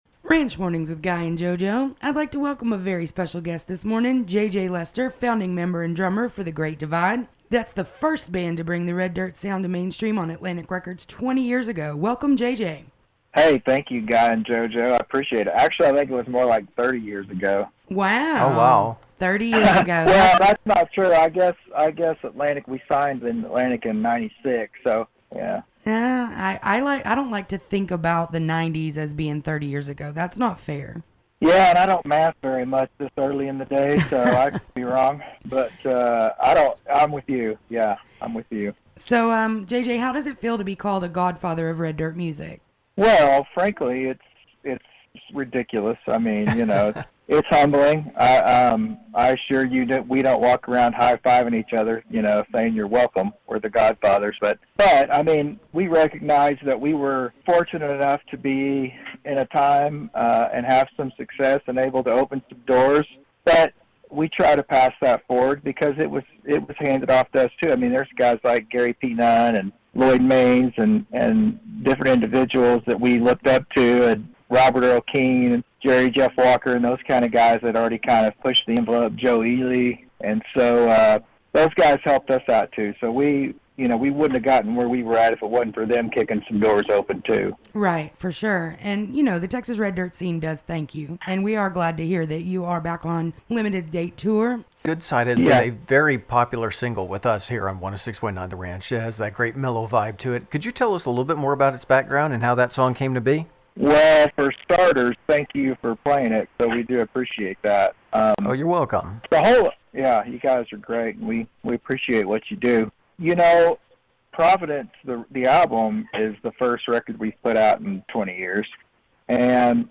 If you missed the interview, you can listen to a recap here: